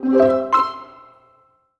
happy_collect_item_05.wav